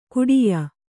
♪ kuḍiya